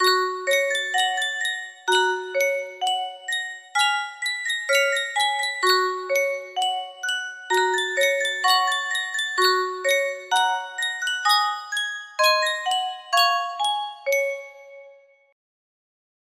Sankyo Music Box - Show Me The Way to Go Home Z- music box melody
Full range 60